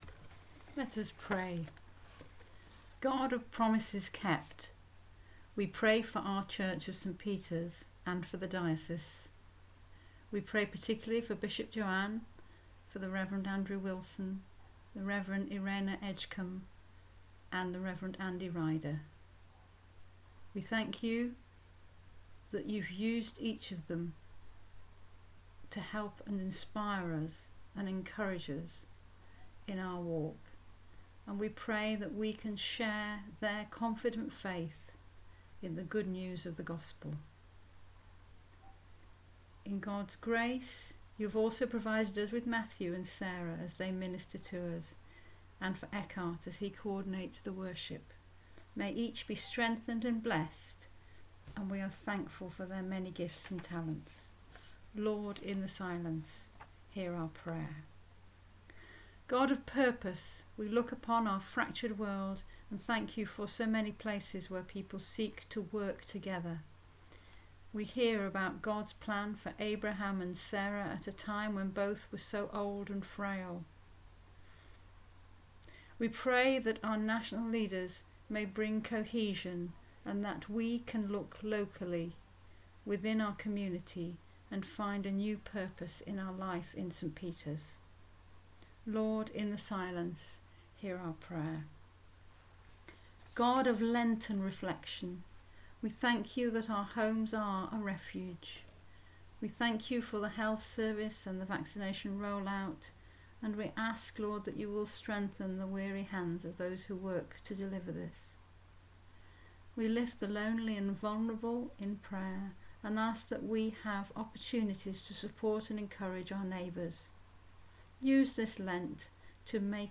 Intercessions
Intercessions-28.2.21.mp3